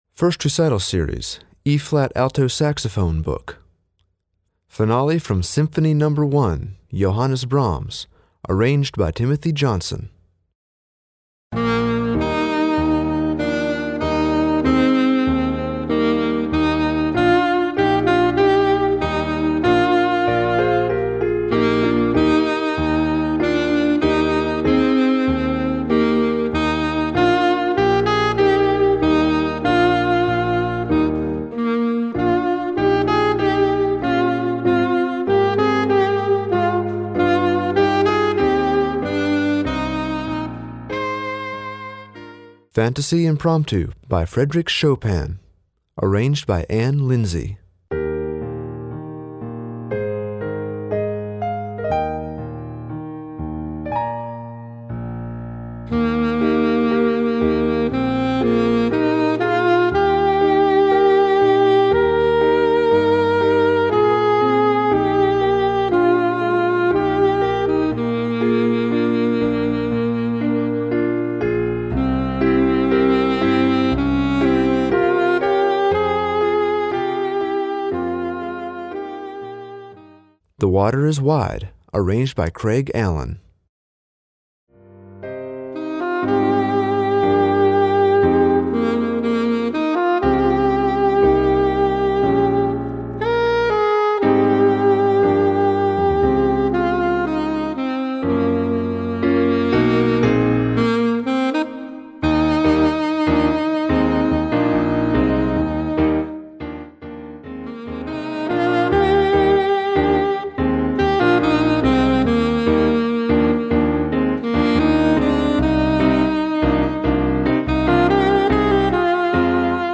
Voicing: Alto Saxophone Collection